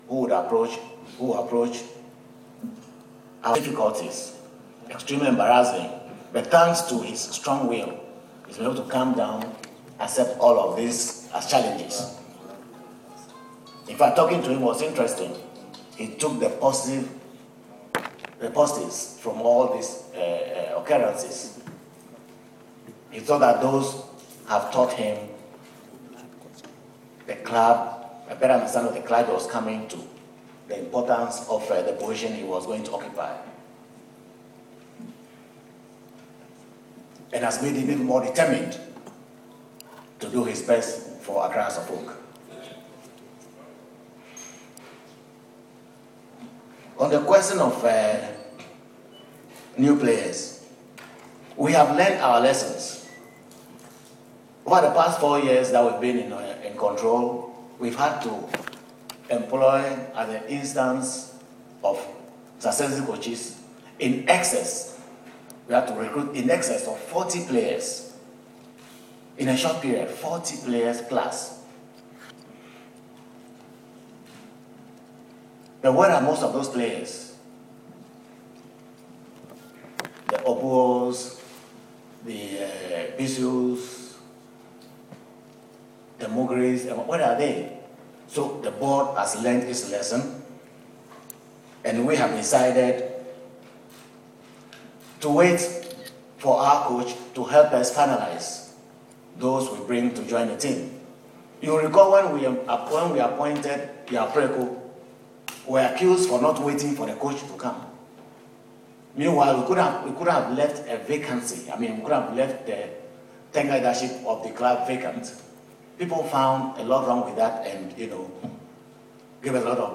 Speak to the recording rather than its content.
Speaking at the club's fifth Annual General Meeting at the Accra International Conference Center on Wednesday, 25th November, 2015, His Royal Highness explains the reason behind the club's approach in the new transfer window.